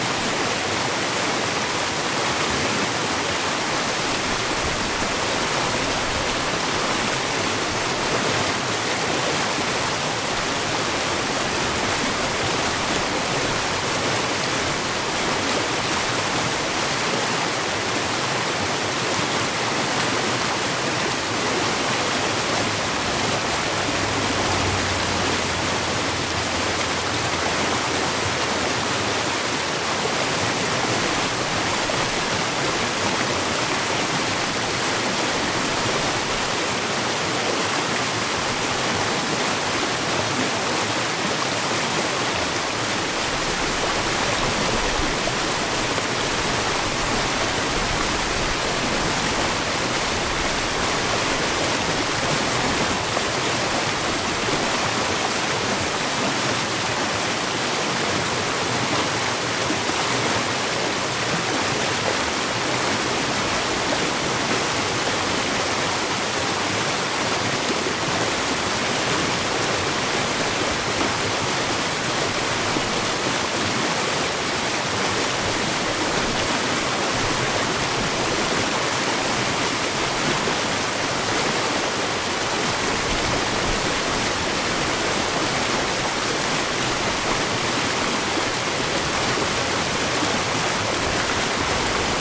随机的 " 水 河流 大的溪流 小的岩石上 宽的
描述：水河大流小岩石宽.flac
声道立体声